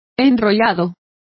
Complete with pronunciation of the translation of convoluted.